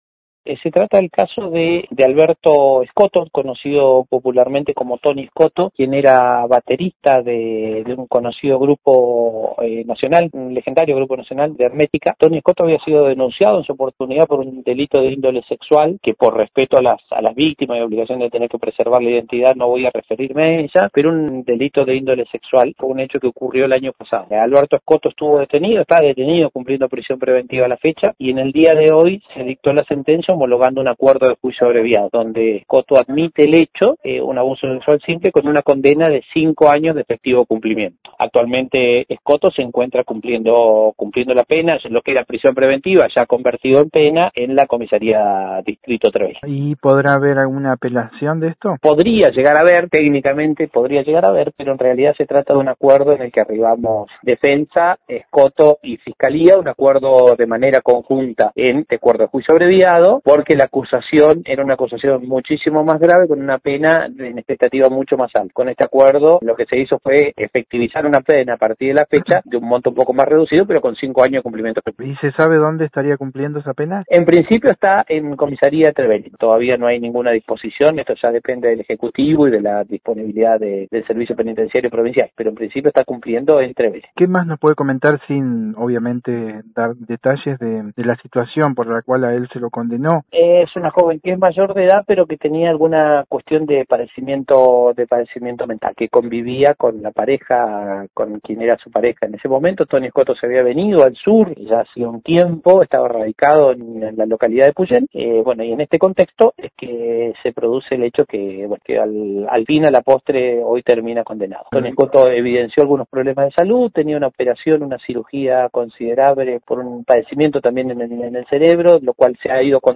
Noticias de Esquel dialogó con el Defensor Público Marcos Ponce, quien confirmó el acuerdo para esta condena.